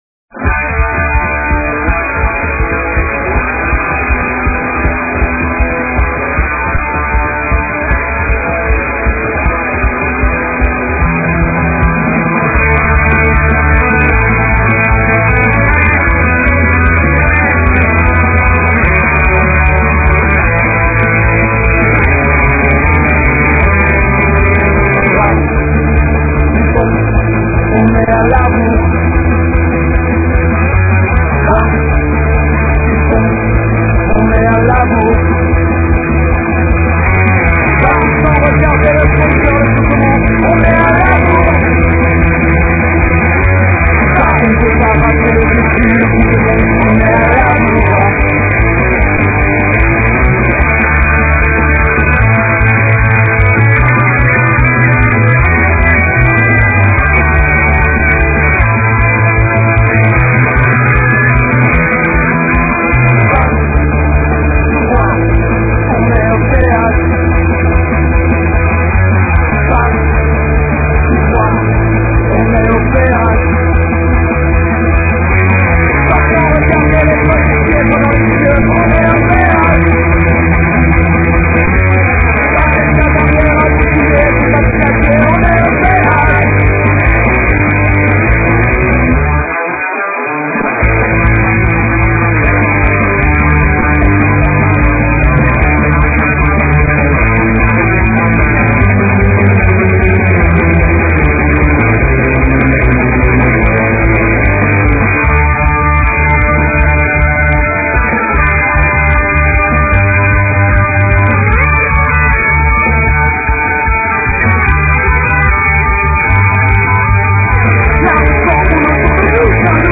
Les fichiers sont en mp3 et de qualité assez mauvaise .